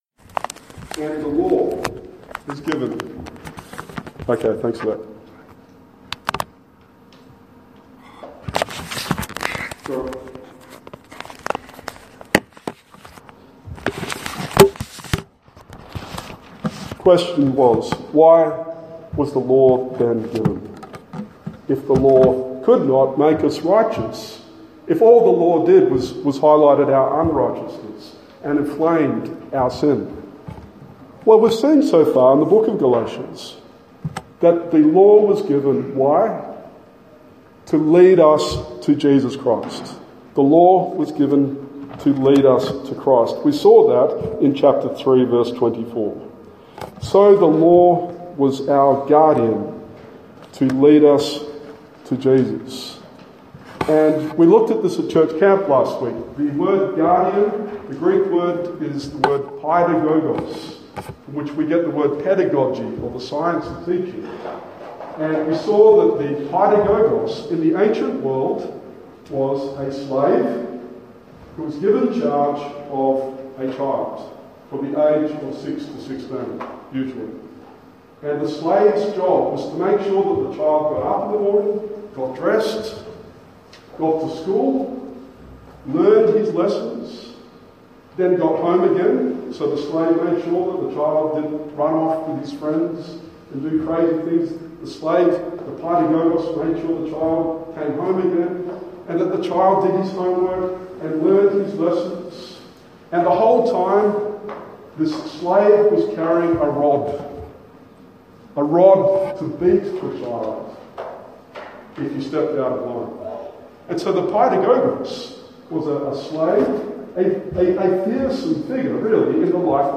Galatians 4:8-31 Sermon